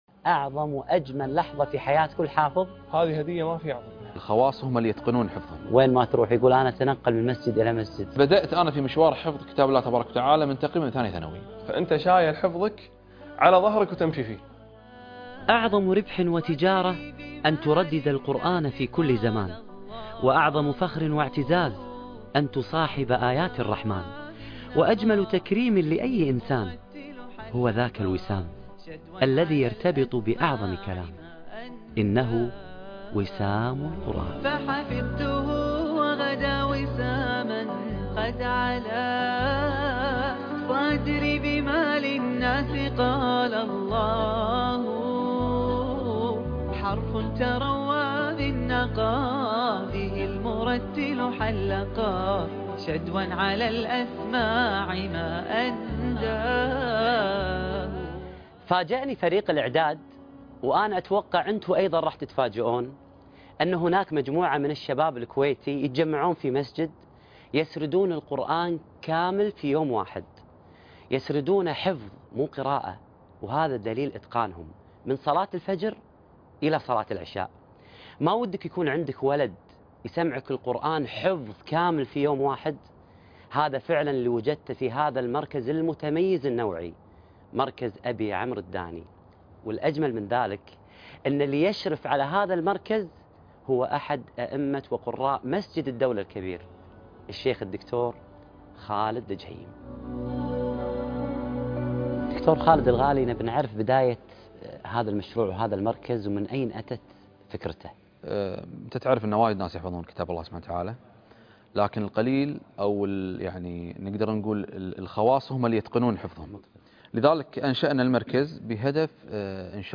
وسام القرآن (الحلقة 11) - القاريء فهد الكندري